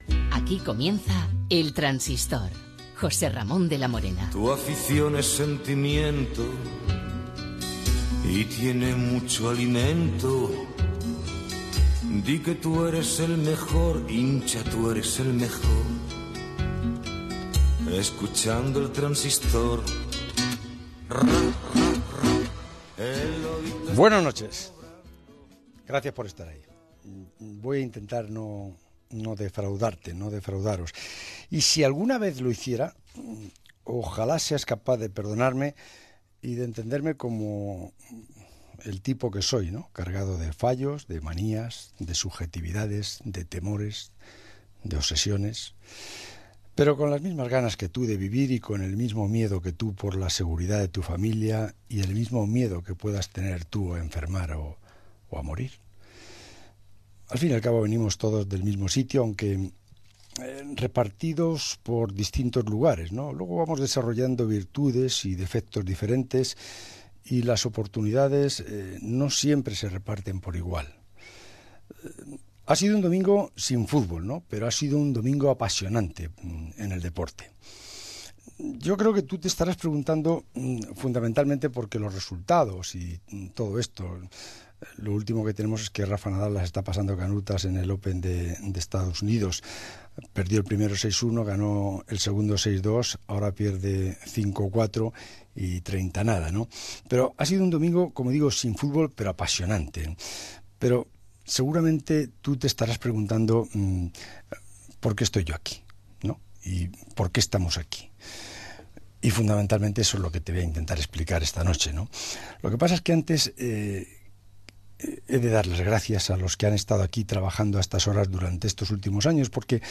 Primera edició del programa i primeres paraules de José Ramón de la Morena des d'Onda Cero.
Esportiu